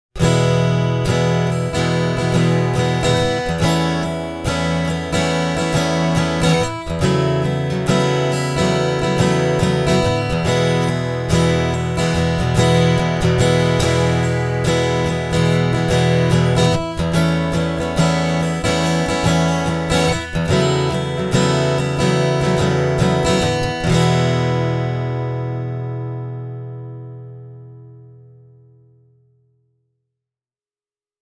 Myös stereoyhdistelmä molemmista signaaleista kuulostaa hyvältä:
Yhdistelmä kuuluu tältä: